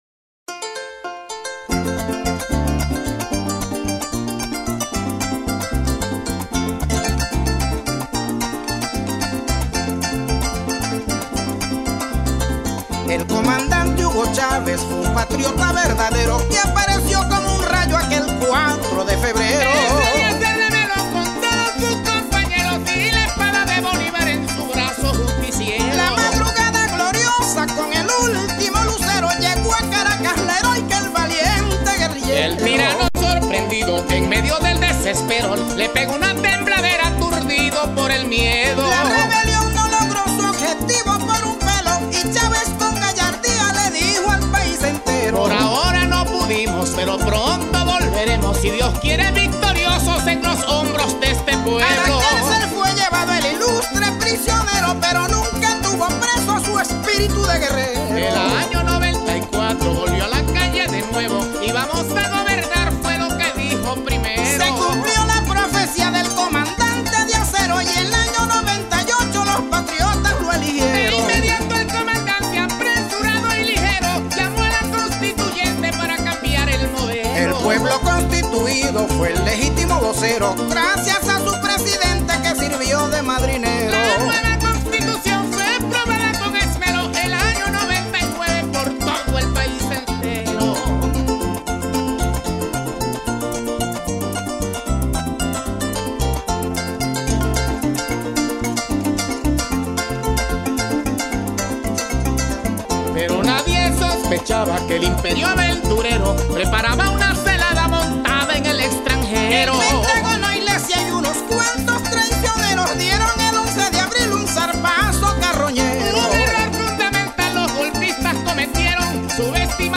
Mi homenaje a Chávez en sus 71 años en letra y música tituladoSiempre Victorioso” (Golpe llanero)